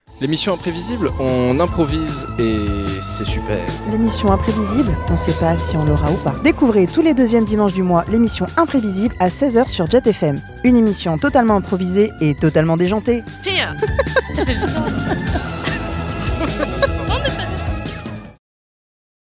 exemple bande annonce radio
Voix off